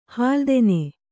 ♪ hāldene